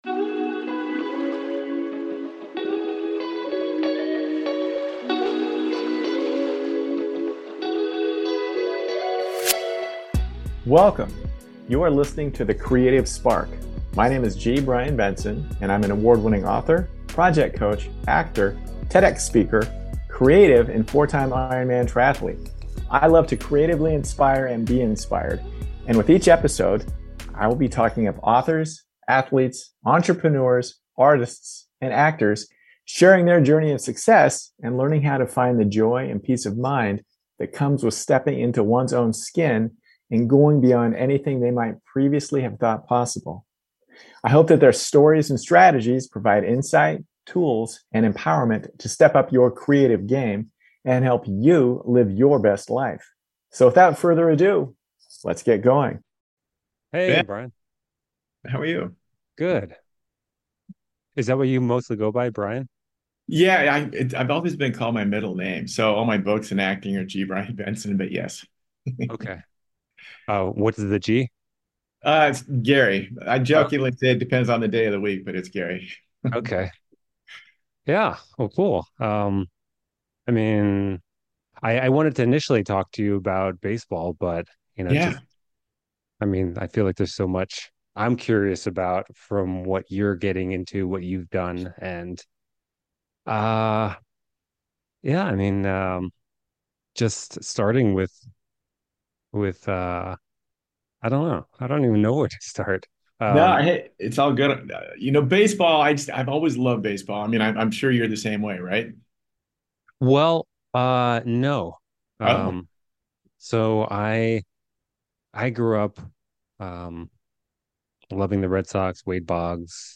I really feel there is something for everyone in this chat.